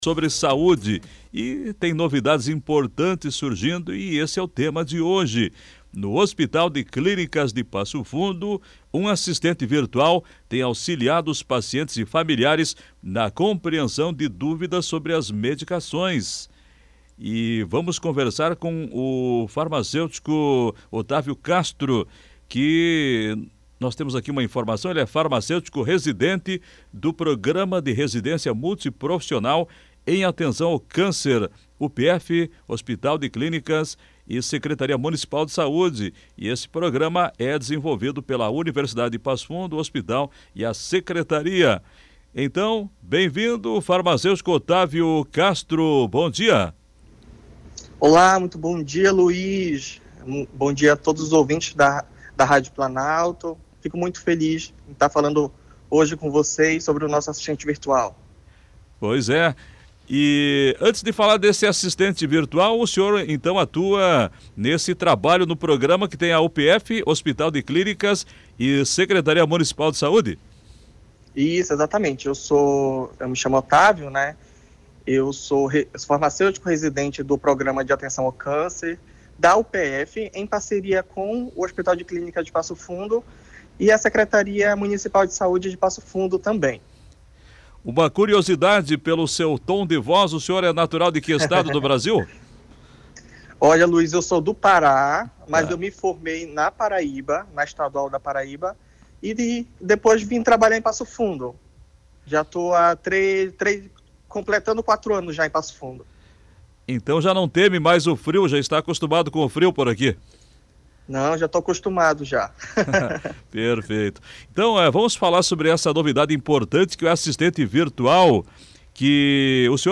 Entrevista/Saúde: Hospital de Clínicas conta com assistente virtual para cuidado farmacêutico oncológico